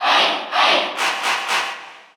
Category: Crowd cheers (SSBU) You cannot overwrite this file.
Ike_Cheer_Russian_SSBU.ogg